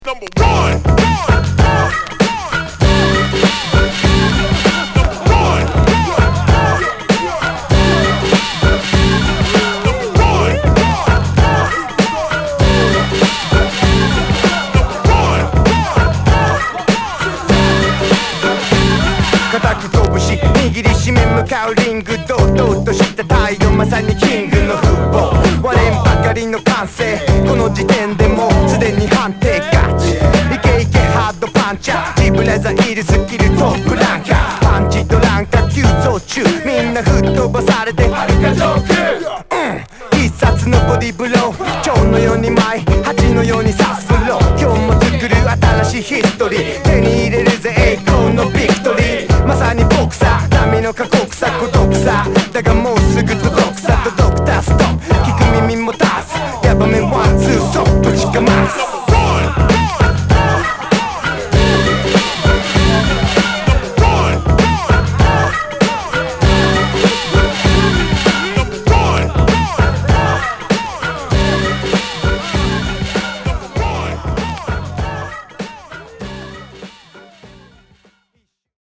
ホーム HIP HOP JAPANESE 12' & LP Z
日本語ラップ・クラシック!!